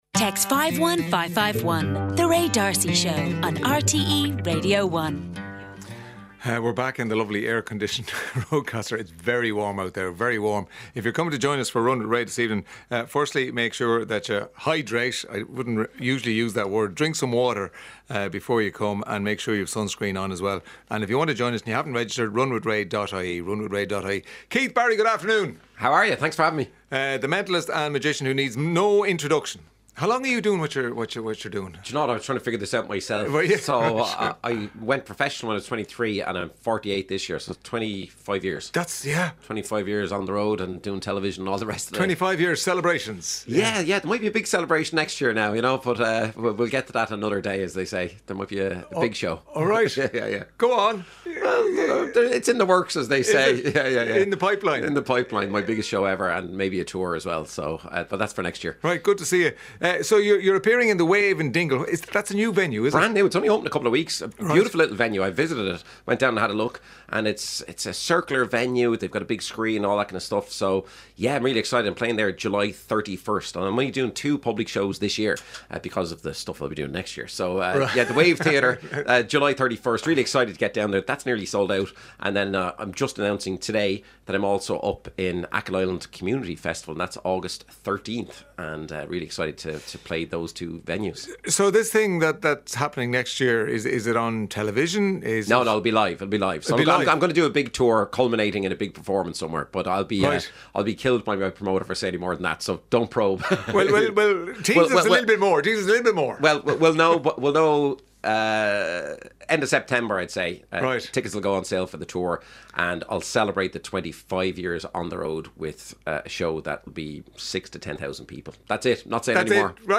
World renowned mentalist, brain hacker and magician Keith Barry, joins Ray in the Roadcaster.